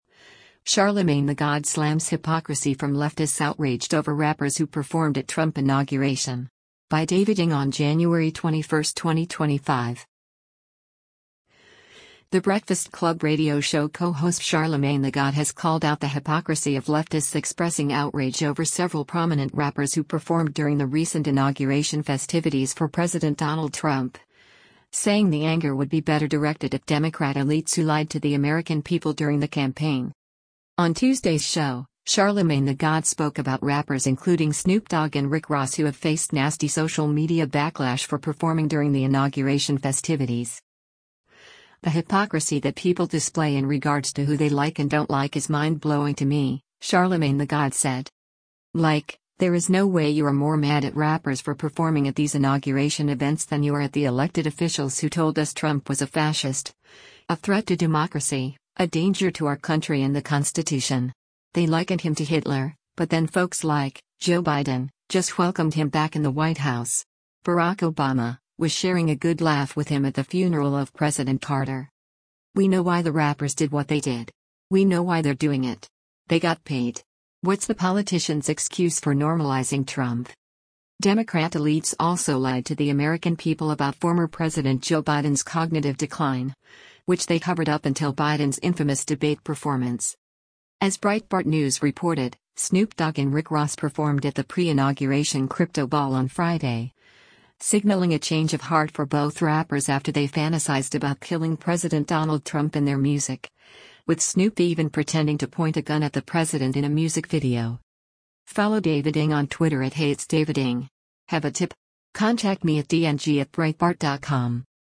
On Tuesday’s show, Charlamagne tha God spoke about rappers including Snoop Dogg and Rick Ross who have faced nasty social media backlash for performing during the inauguration festivities.